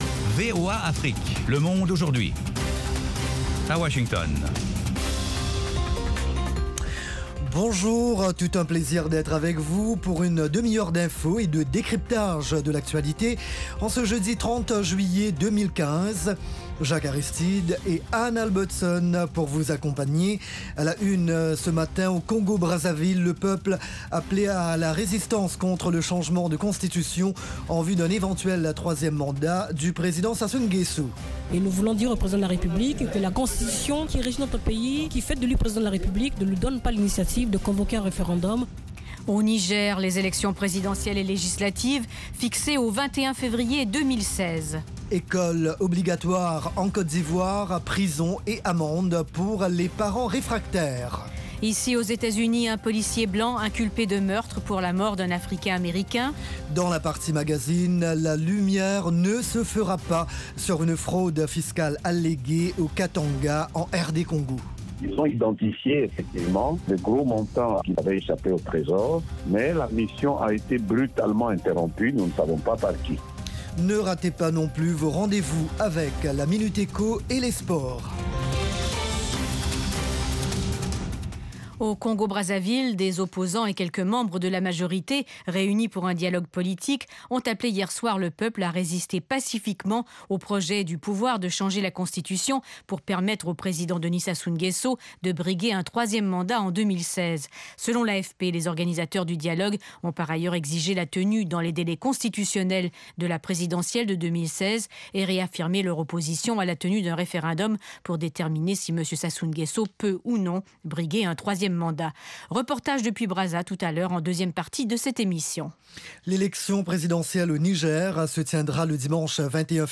Toute l’actualité sous-régionale sous la forme de reportages et d’interviews.